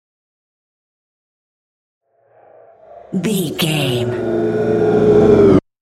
Technologic riser human robot
Sound Effects
Atonal
bouncy
bright
driving
futuristic
high tech
intense
tension
riser
sci fi